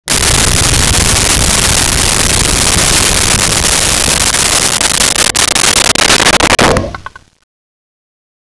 Creature Snarl 03
Creature Snarl 03.wav